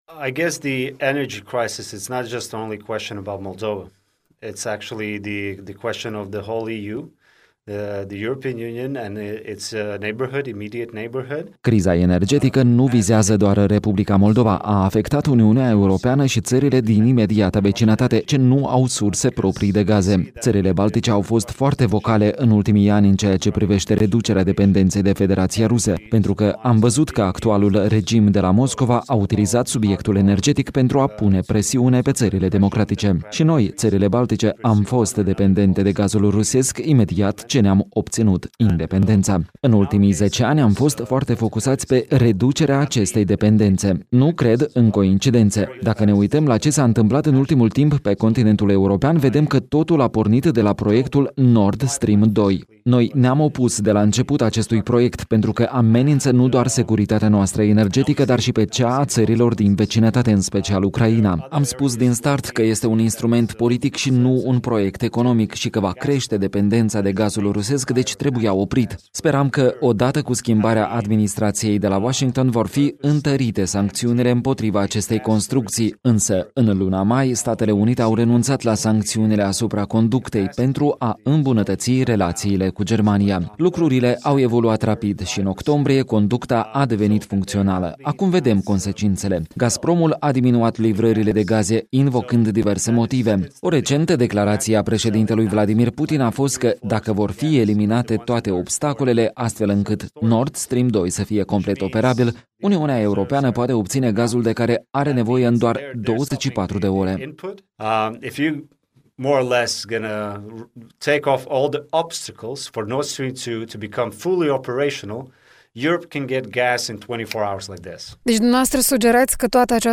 Președintele Comitetului pentru afaceri externe al Parlamentului Letoniei, Rihards Kols, în studioul Europei Libere